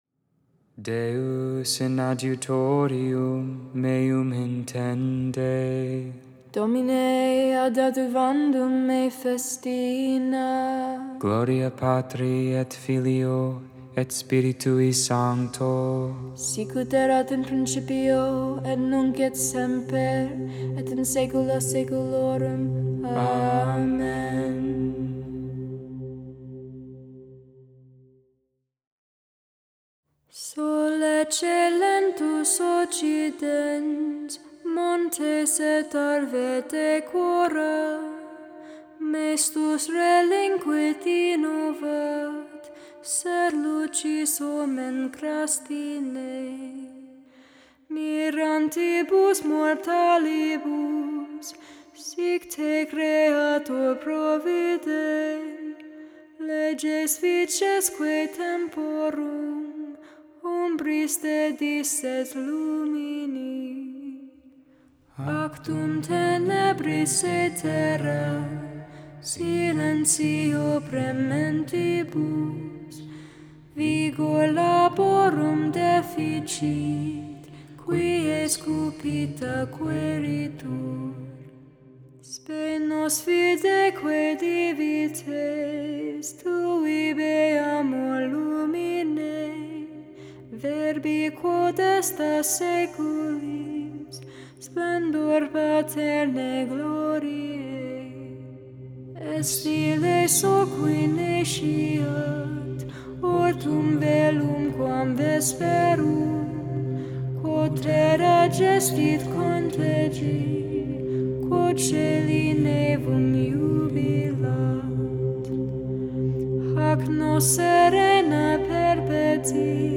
Psalm 62 (Tone 1) Psalm 67 (Tone 2)